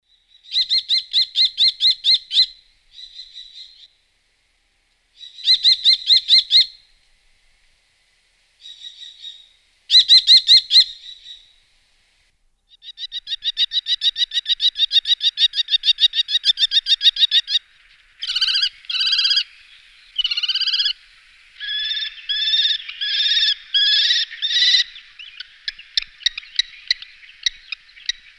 Orto botanico - Gheppio
gheppio.mp3